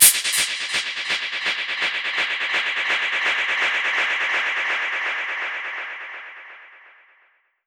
Index of /musicradar/dub-percussion-samples/125bpm
DPFX_PercHit_D_125-05.wav